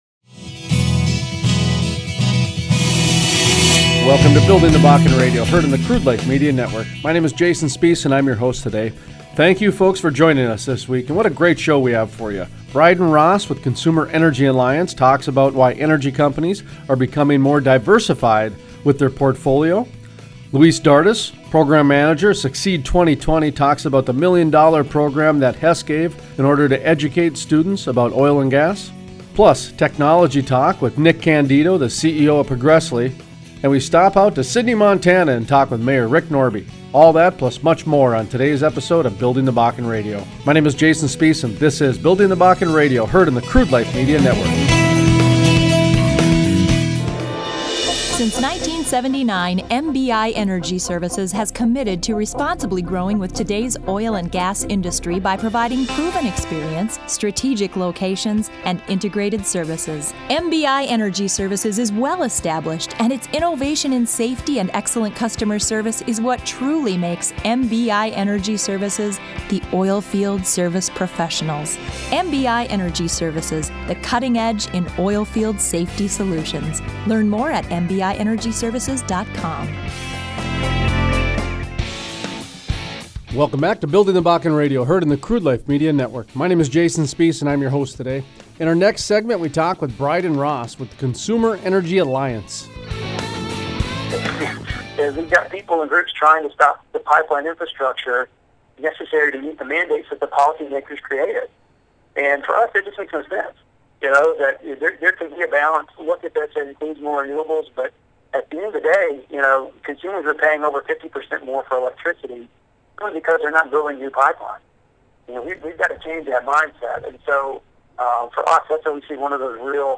Interview: Dr. Neal Barnard, physician and author, Physicians Committee for Responsible Medicine Talks about his new book “The Cheese Trap” and a few tips on healthy lifestyles.